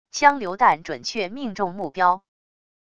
枪榴弹准确命中目标wav音频